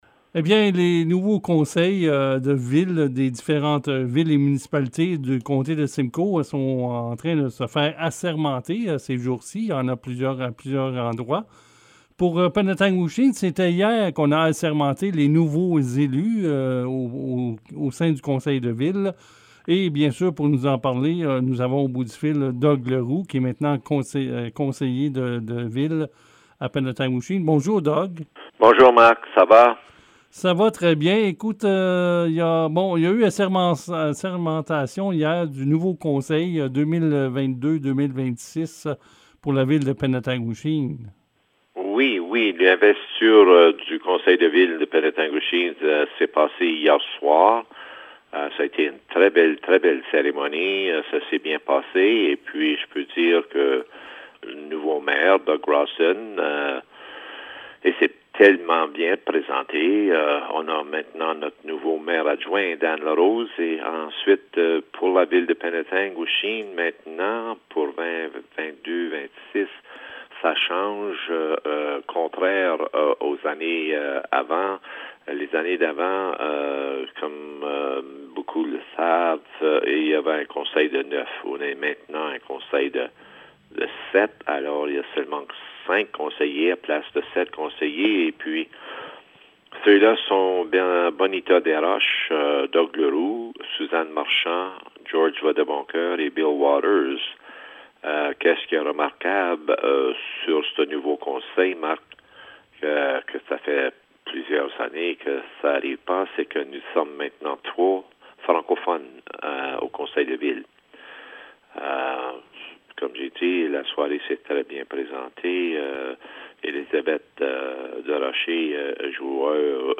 Lors du rendez-vous Penetanguishene sur CFRH, le maire sortant et maintenant conseiller Doug Leroux a bien voulu résumer le tout.